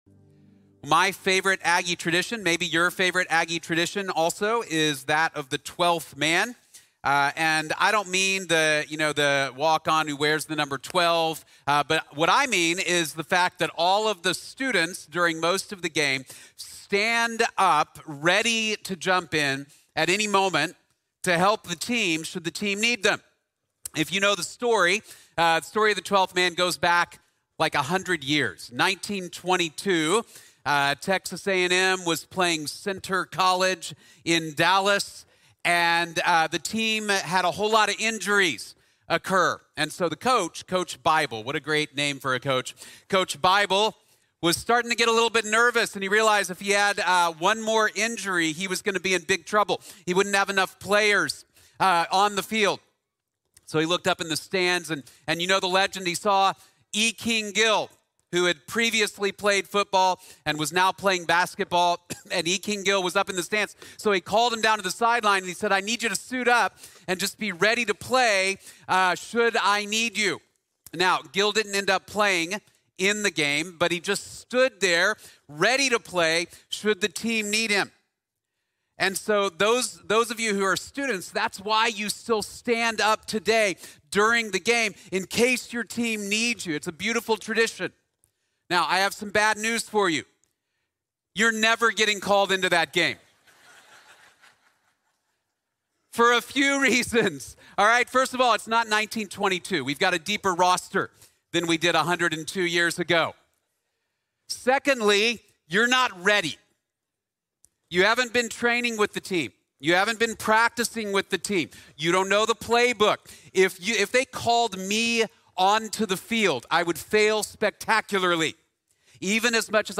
Character Under Pressure | Sermon | Grace Bible Church